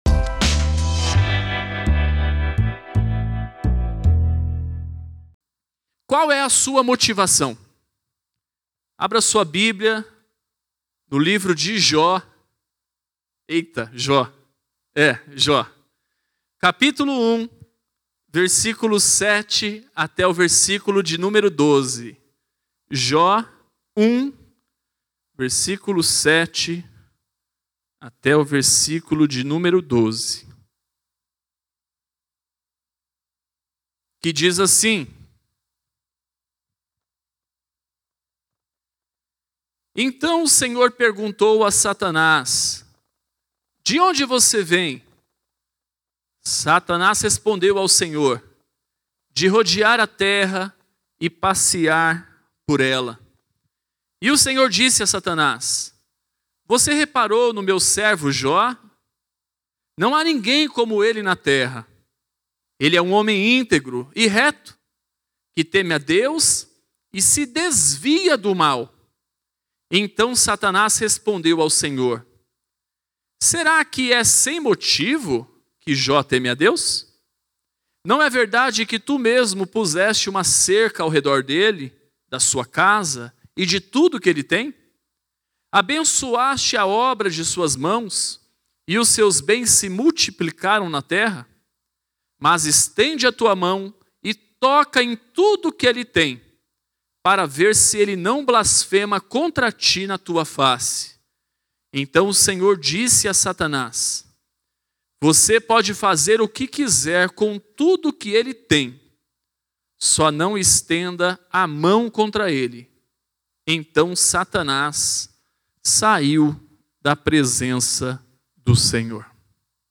Mensagem